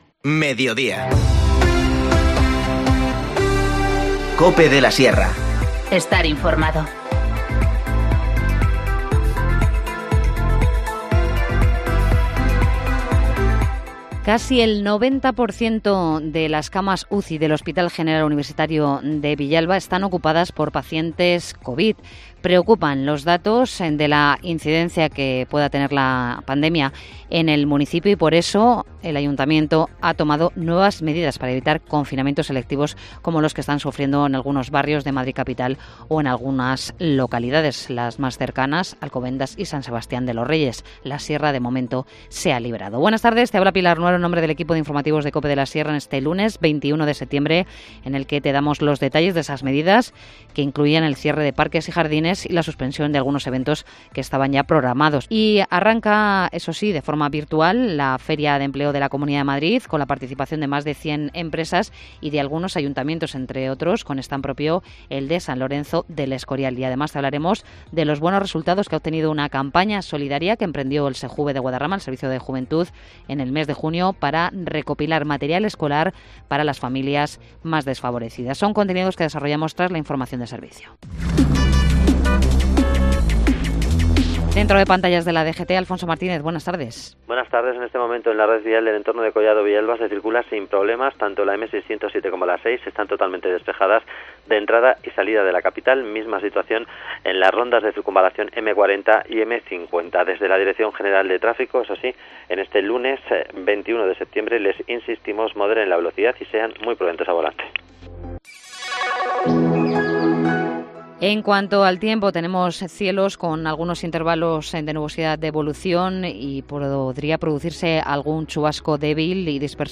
Informativo Mediodía 21 septiembre